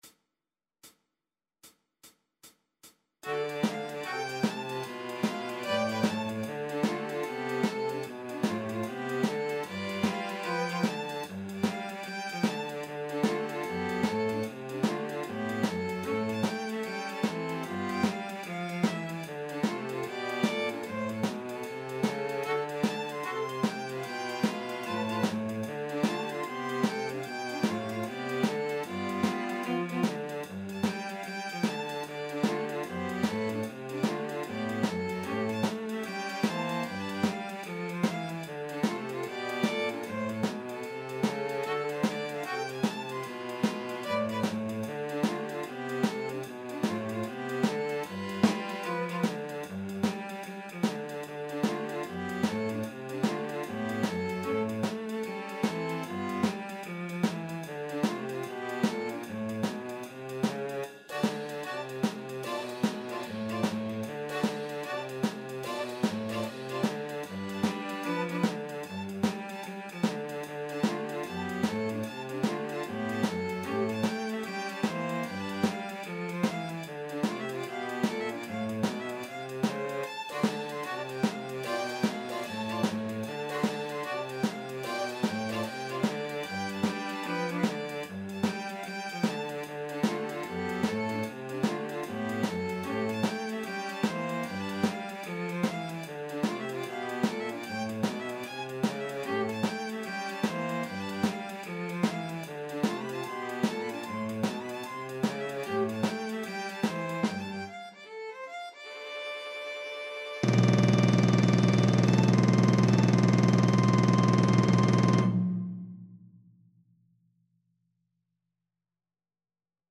String Quartet version
Violin 1Violin 2ViolaCelloPercussion
4/4 (View more 4/4 Music)
Jazz (View more Jazz String Quartet Music)